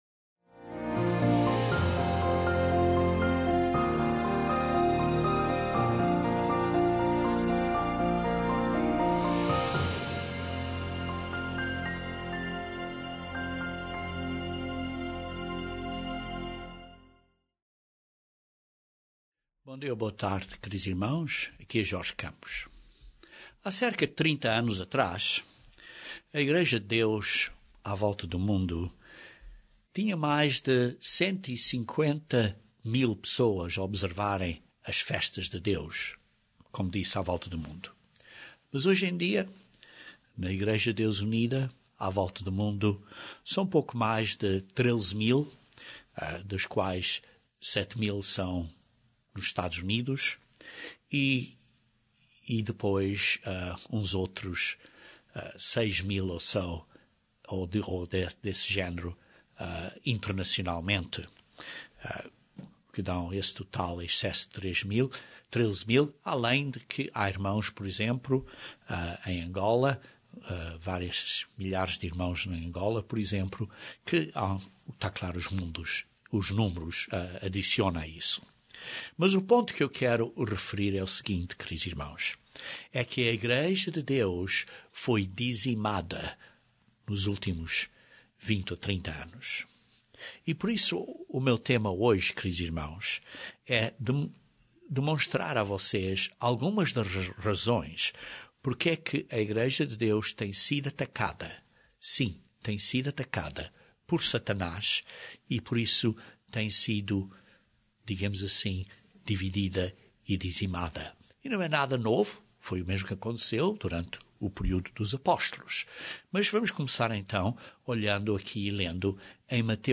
Satanás tem uma intenção de destruir o poder do povo de Deus (Daniel 12:7). Este sermão descreve esta meta que Satanás tem. Vemos como ele tem usado duas táticas no passado e continua usando.